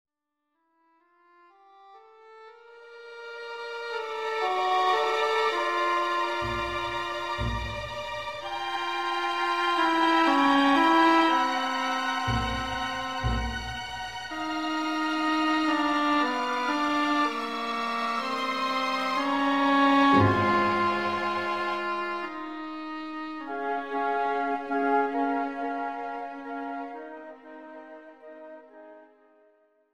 Classical and Opera